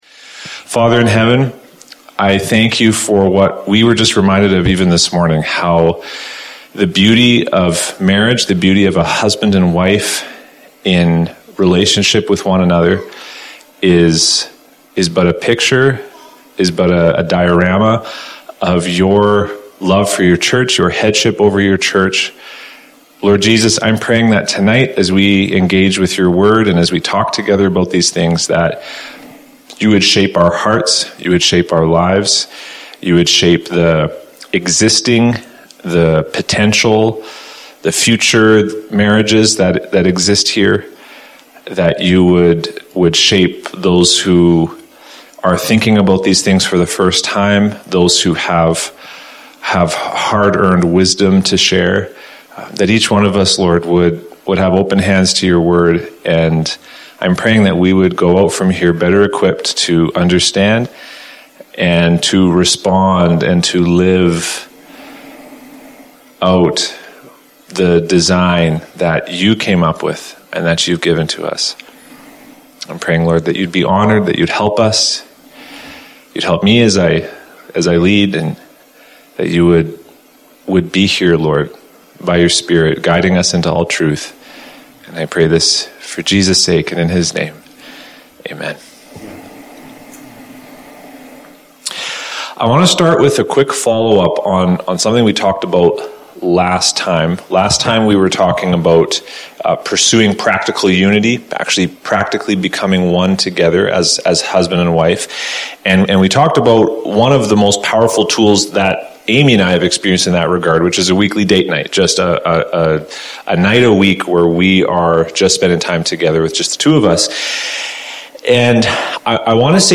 Marriage Workshop #4